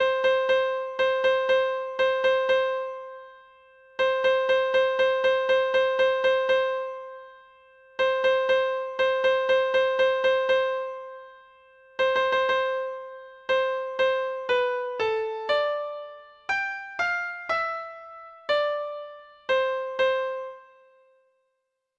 Folk Songs from 'Digital Tradition' Letter O Old Soldiers (2)
Free Sheet music for Treble Clef Instrument
Traditional Music of unknown author.